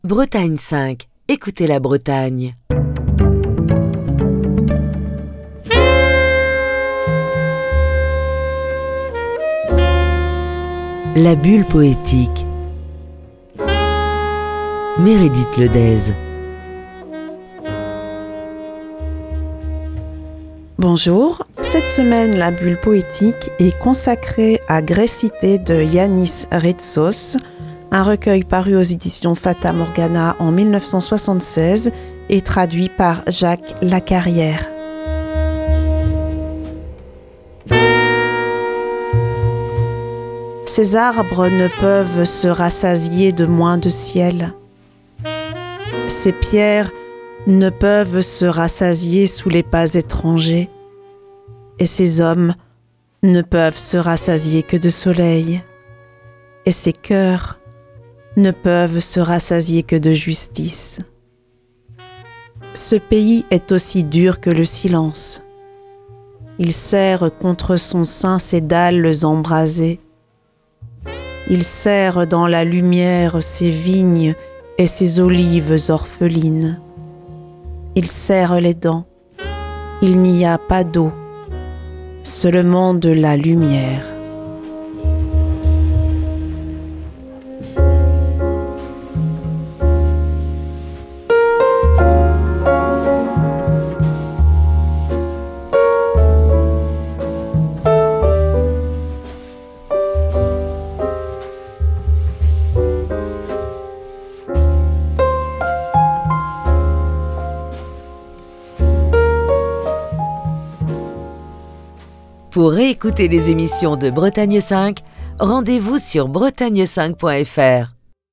lit cette semaine des textes du poète grec Yánnis Rítsos